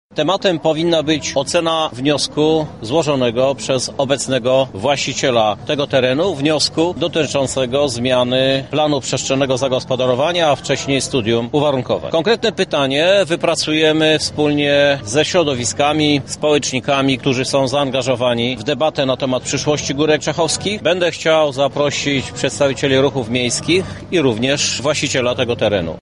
W tym referendum chcemy zawrzeć więcej niż jedno pytanie, ale wszystkie one mają dotyczyć Górek Czechowskich – mówi prezydent Krzysztof Żuk